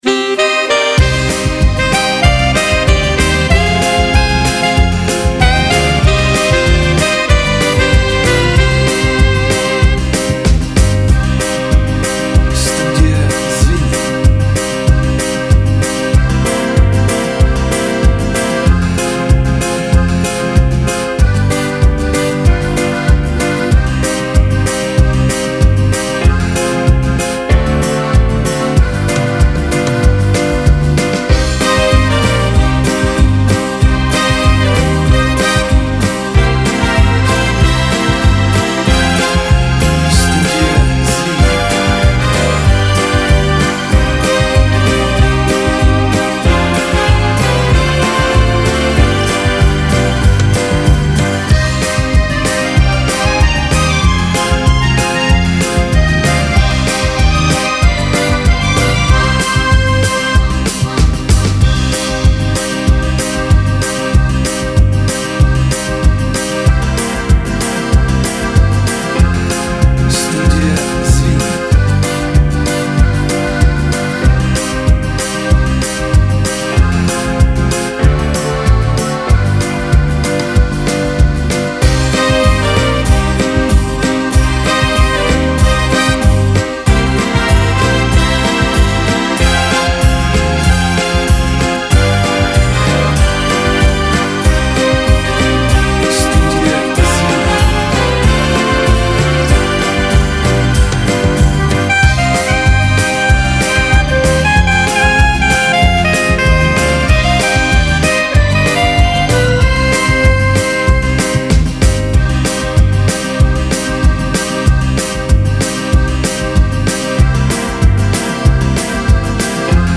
Примеры минусовок песен заказчиков.
Русский Шансон